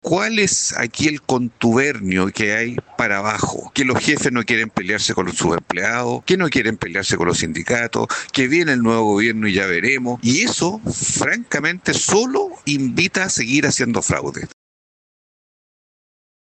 Al respecto, el senador socialista Juan Luis Castro emplazó el compromiso que el exministro Mario Marcel hizo en su momento y que finalmente no se cumplió.